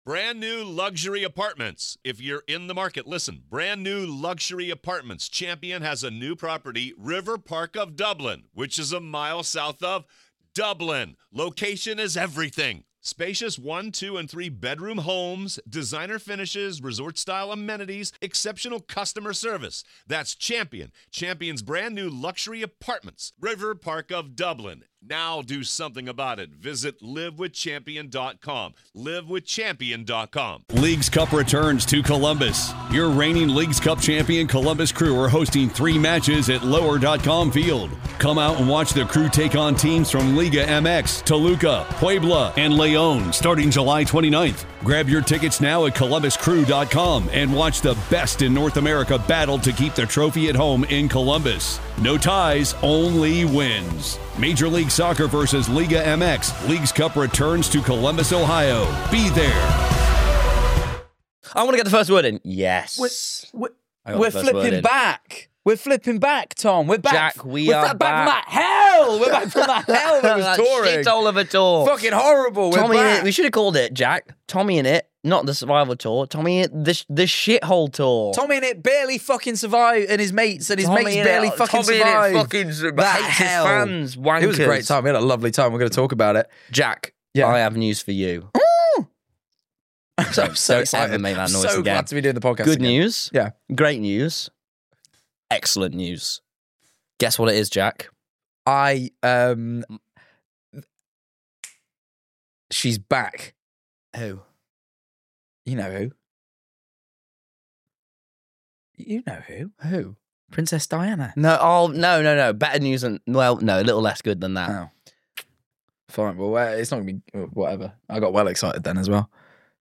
Written & presented by: Tom Simons & Jack Manifold